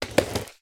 SD_SFX_RollerSkate_Step_2.wav